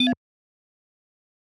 tudum.wav